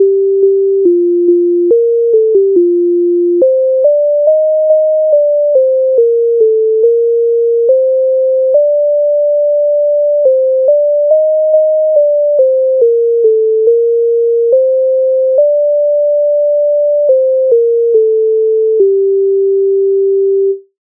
Українська народна пісня Колядка